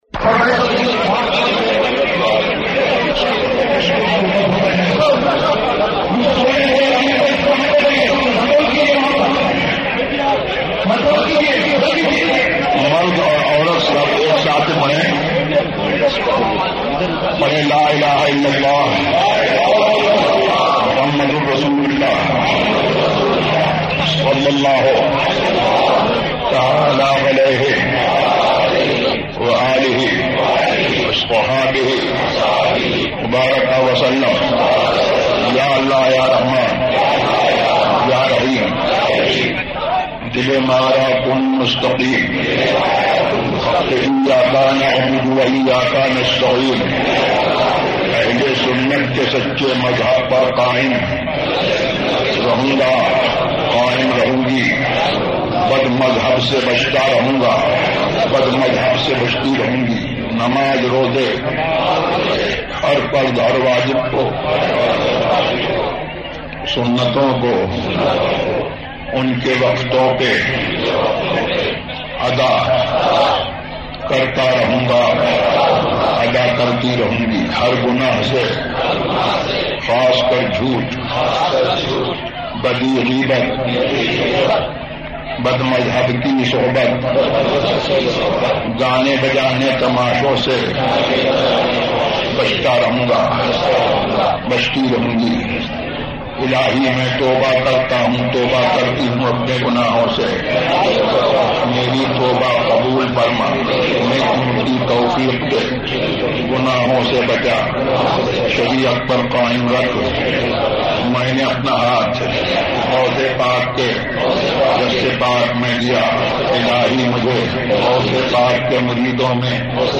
ایمان کی تازگی ZiaeTaiba Audio میڈیا کی معلومات نام ایمان کی تازگی موضوع تقاریر آواز تاج الشریعہ مفتی اختر رضا خان ازہری زبان اُردو کل نتائج 1078 قسم آڈیو ڈاؤن لوڈ MP 3 ڈاؤن لوڈ MP 4 متعلقہ تجویزوآراء